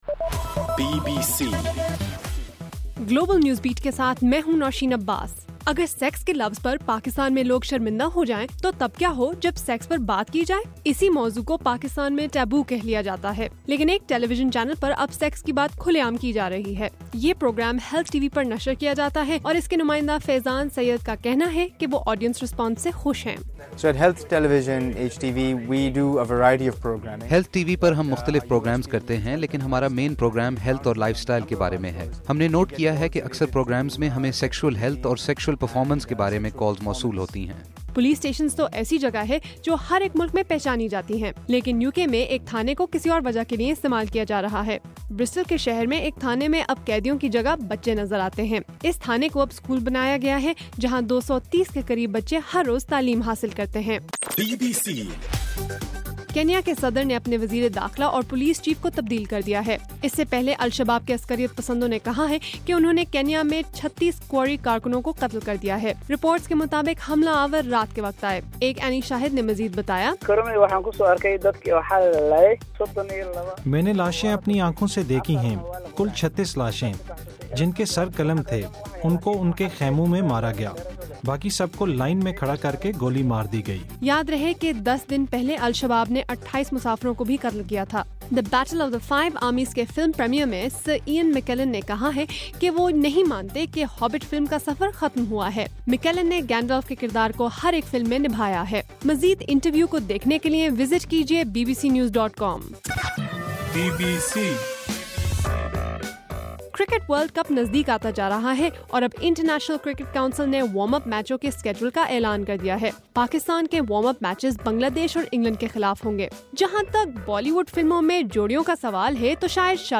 دسمبر 2: رات 11 بجے کا گلوبل نیوز بیٹ بُلیٹن